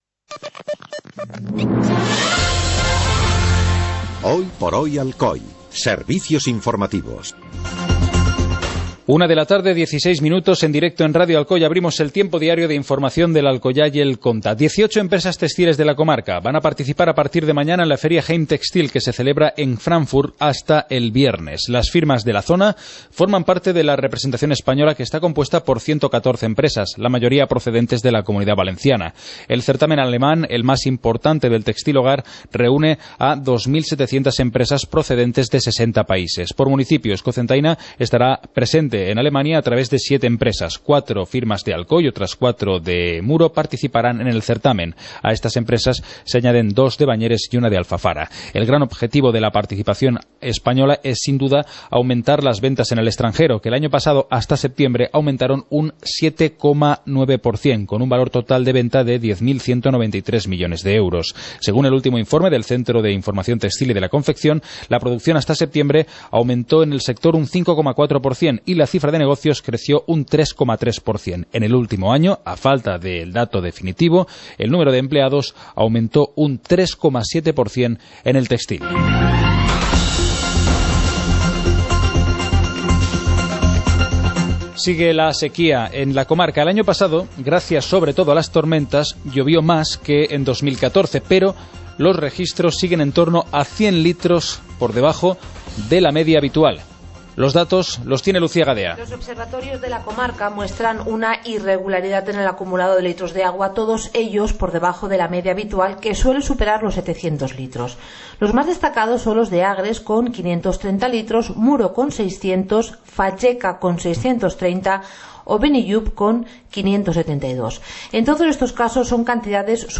Informativo comarcal - lunes, 11 de enero de 2016